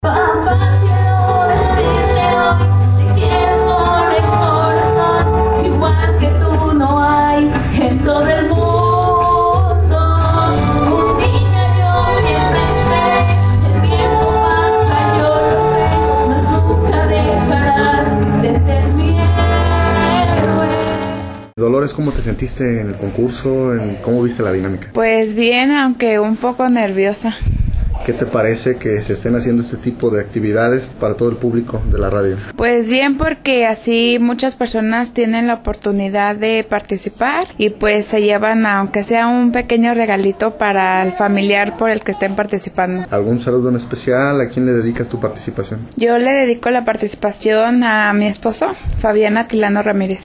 CONCURSO CON MOTIVO DEL DIA DEL PADRE EN LA RADIO
En otro asunto, tenemos que agradecer a todos aquellas personas que participaron mandando su video y también aquellas personas que votaron por el que más les gusto, la final del concurso de canto en honor al día del padre se llevó a cabo aquí en las instalaciones de la radio y los 4 participantes con más likes en redes sociales cantaron completamente en vivo para competir por el primer lugar.